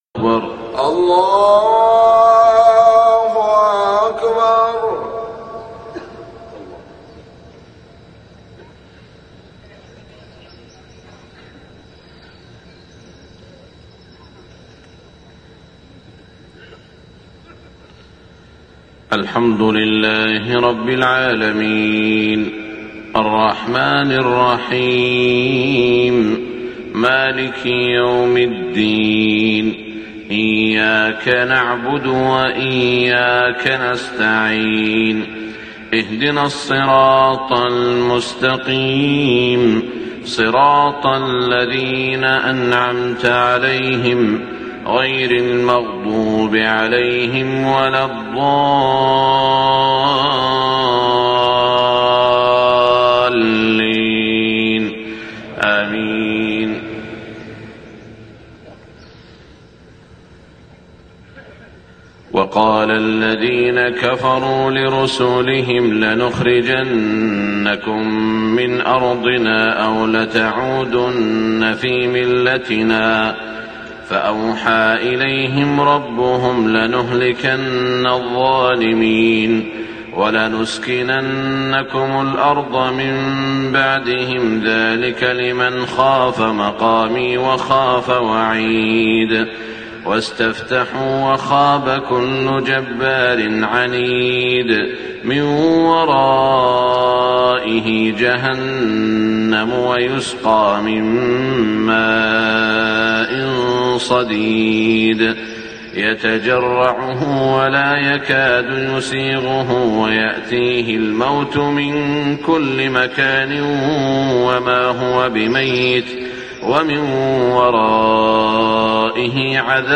صلاة الفجر 1426هـ من سو ة إبراهيم > 1426 🕋 > الفروض - تلاوات الحرمين